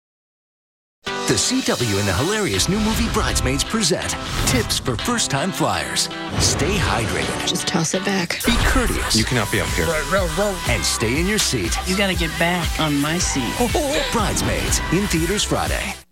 TV Spots